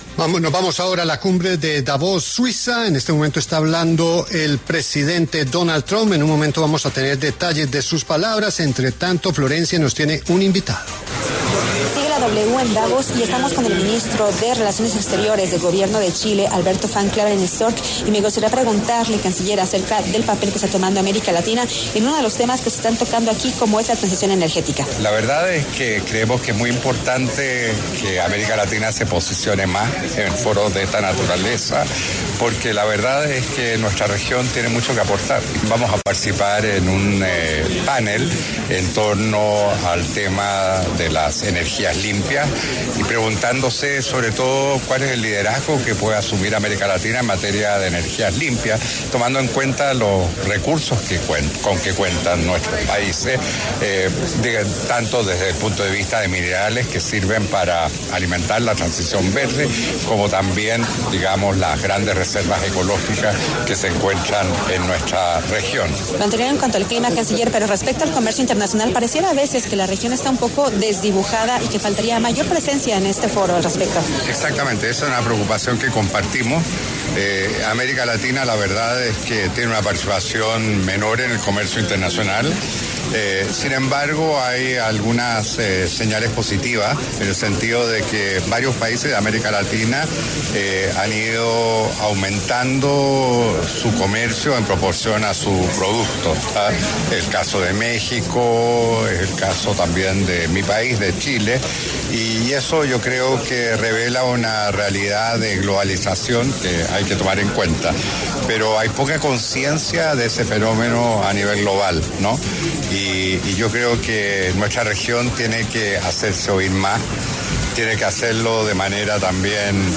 La W continúa el cubrimiento especial desde el Foro Económico Mundial de Davos, en Suiza.